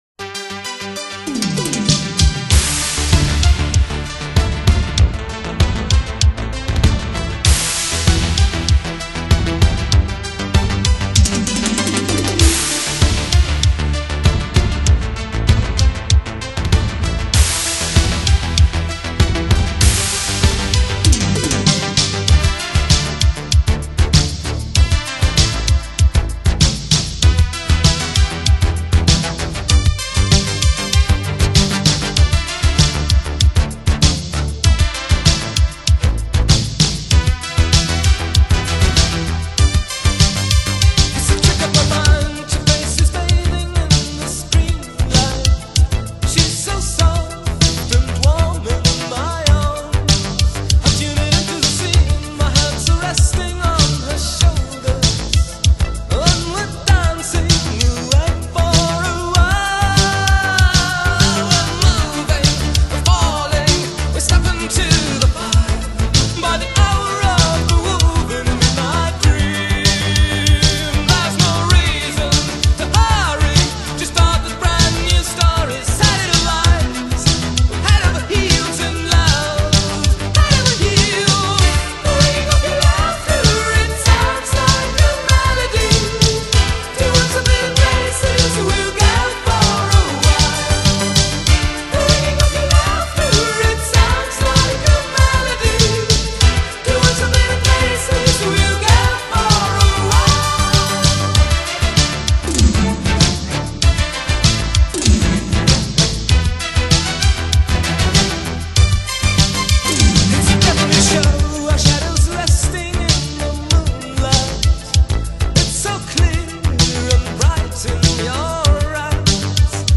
Genre: Electronic, Disco, Synthpop, New Wave, Remixed